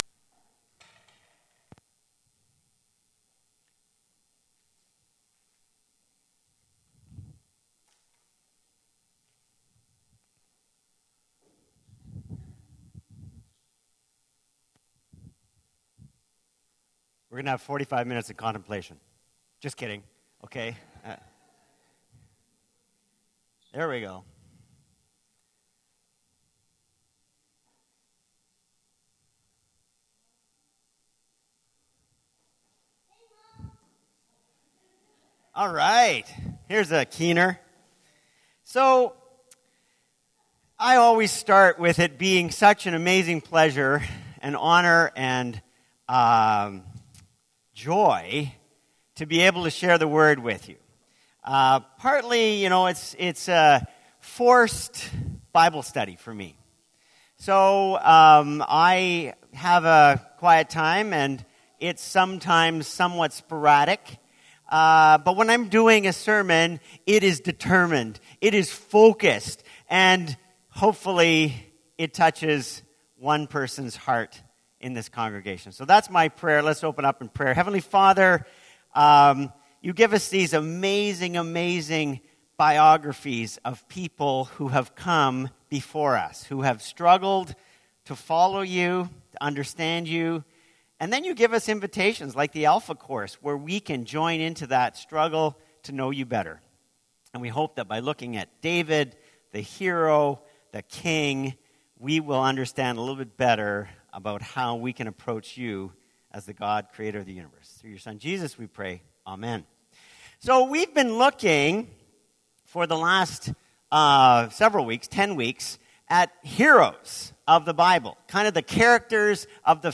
2019 Sermons